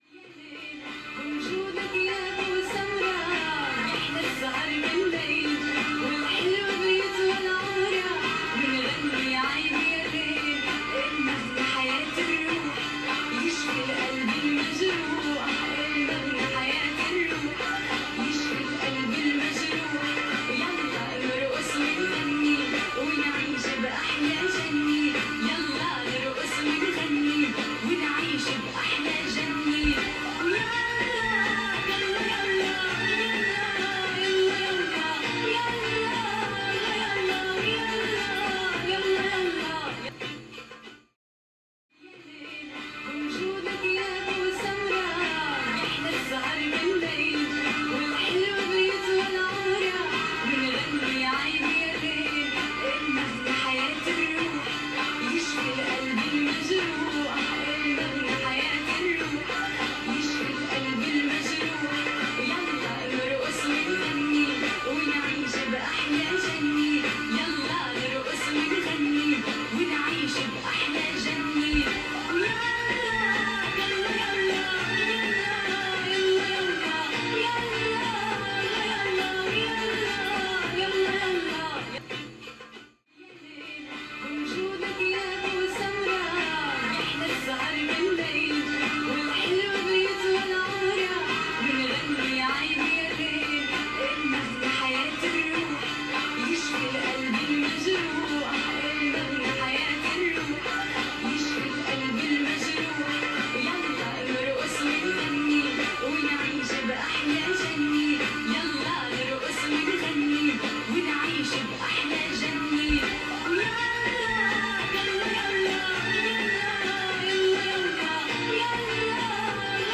a collaborative performance